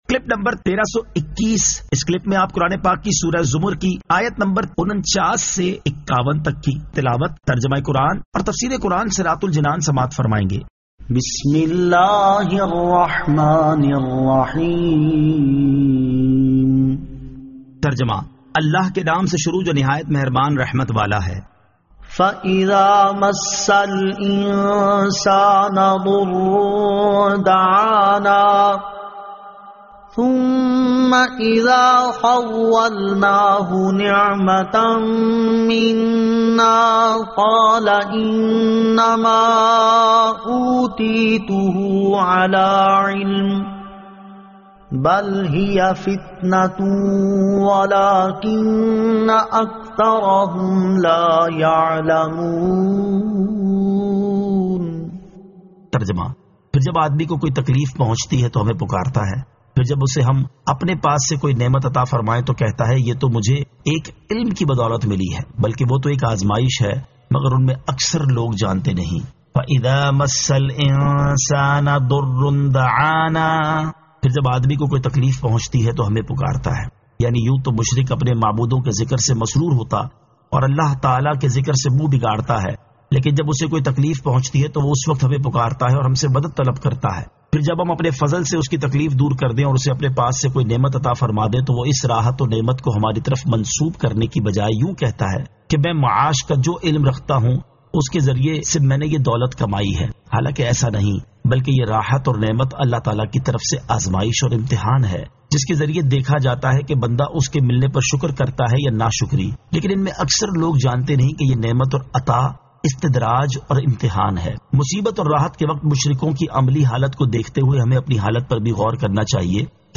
Surah Az-Zamar 49 To 51 Tilawat , Tarjama , Tafseer